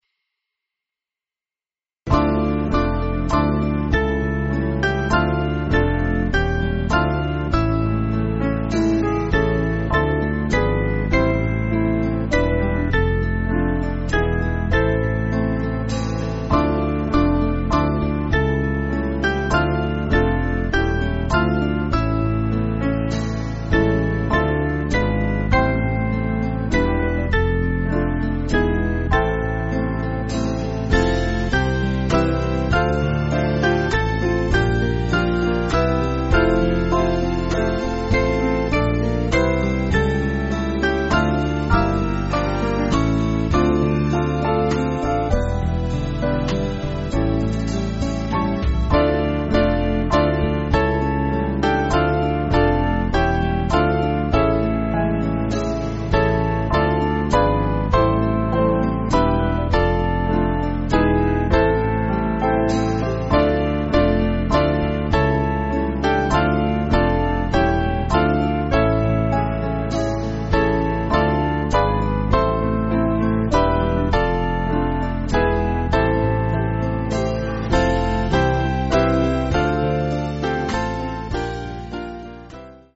Small Band
(CM)   5/Dm